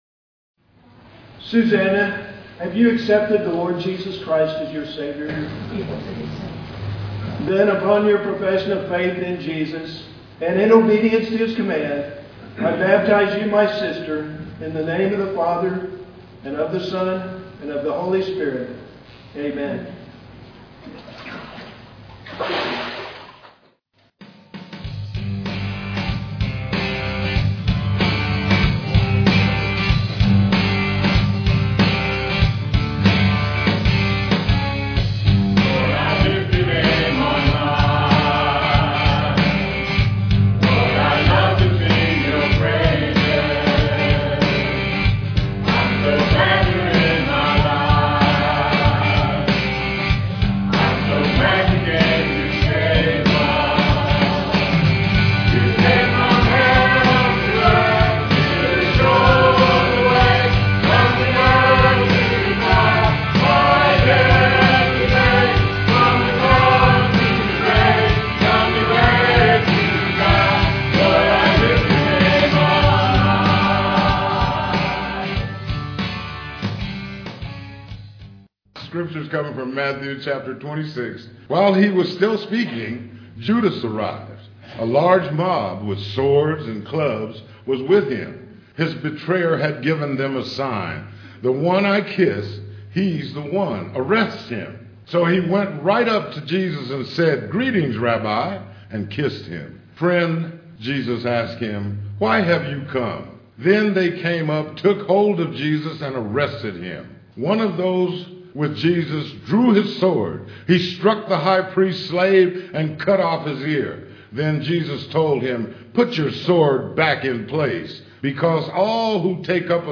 Piano offertory
Solo